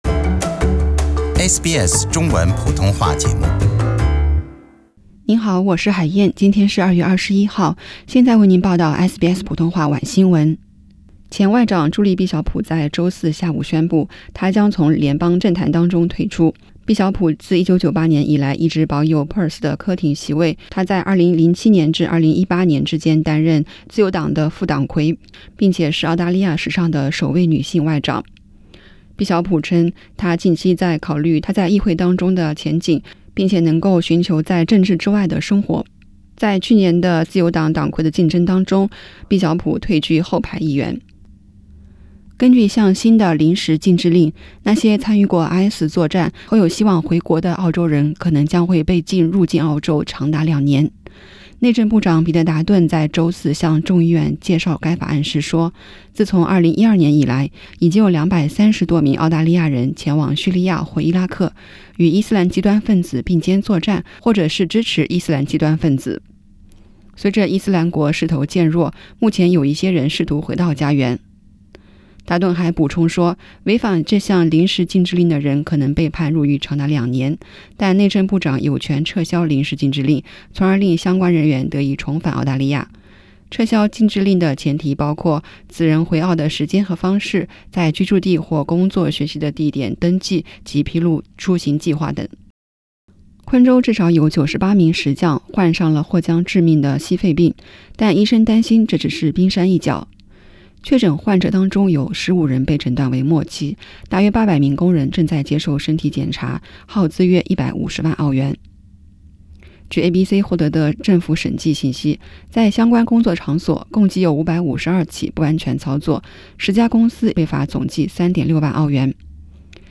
SBS晚新闻（2月21日）